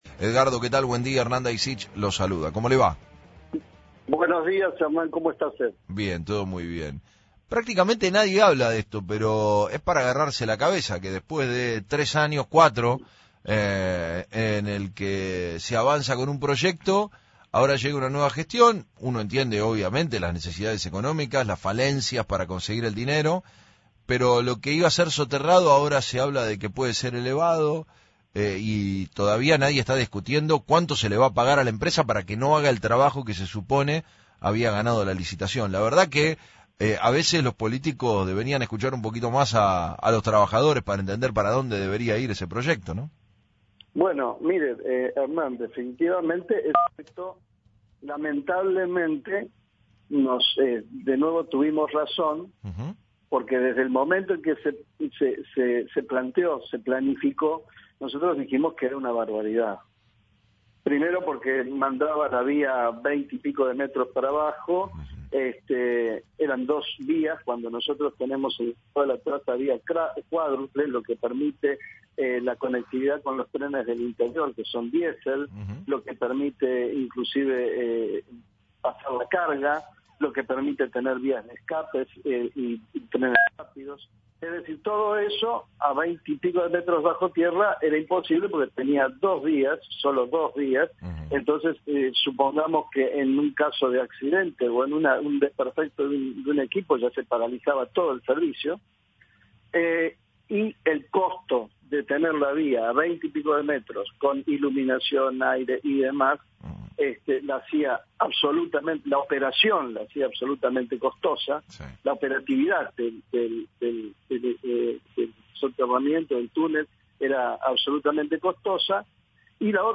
En dialogo con FRECUENCIA ZERO